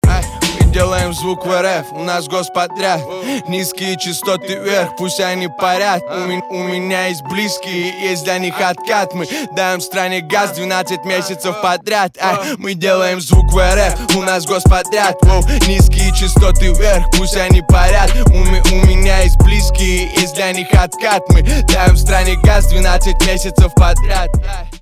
русский рэп
битовые , басы
пианино